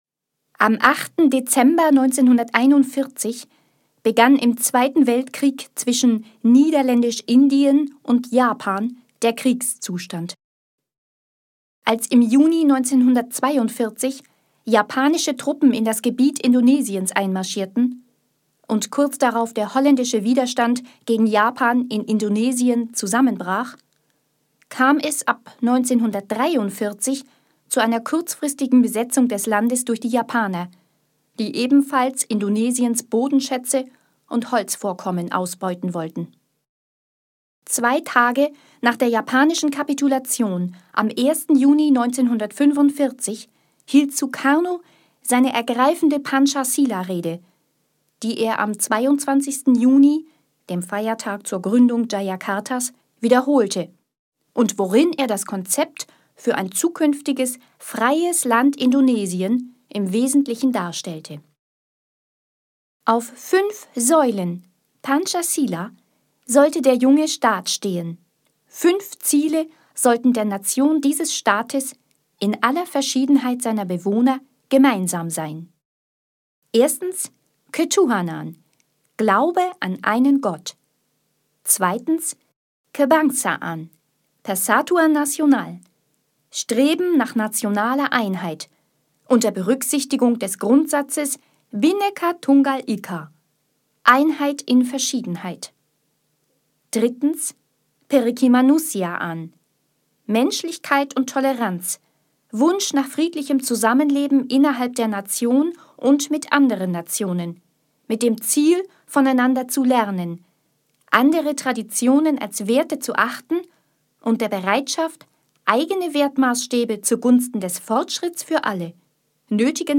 Vortrag : Indonesien – Einheit in Verschiedenheit